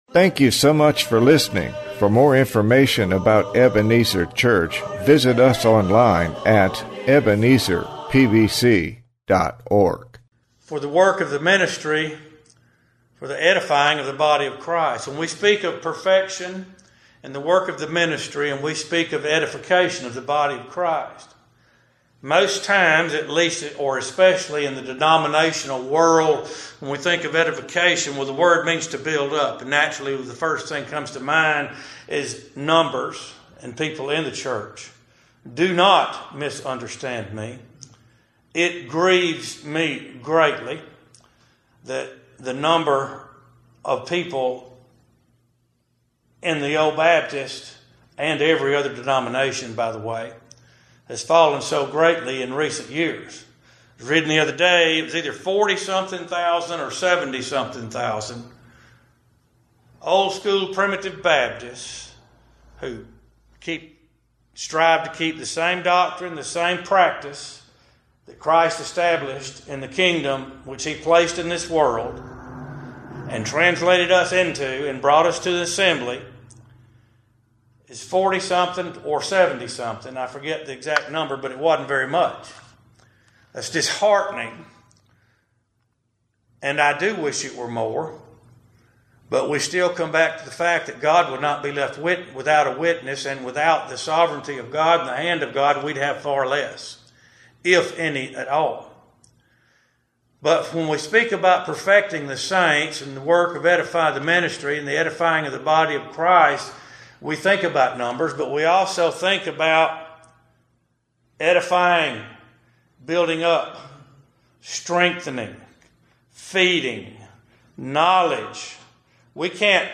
From our worship service, September 23, 2018.